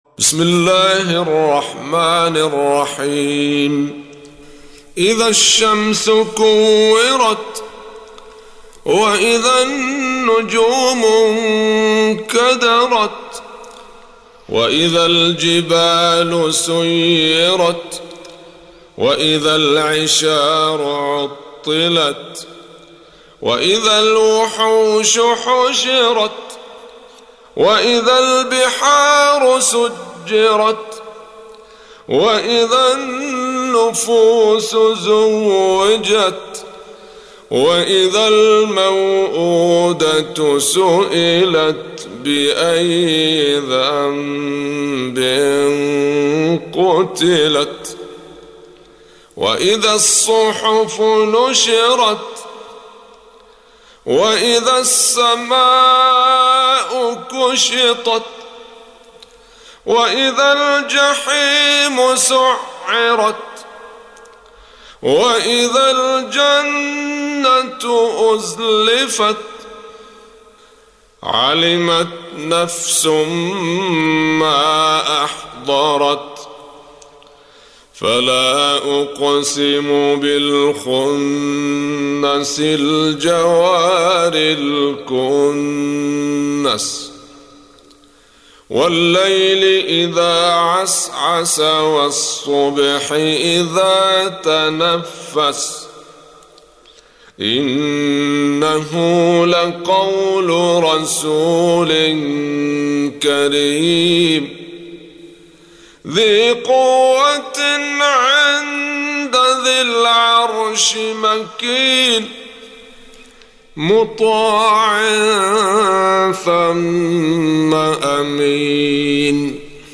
Surah Sequence تتابع السورة Download Surah حمّل السورة Reciting Murattalah Audio for 81. Surah At-Takw�r سورة التكوير N.B *Surah Includes Al-Basmalah Reciters Sequents تتابع التلاوات Reciters Repeats تكرار التلاوات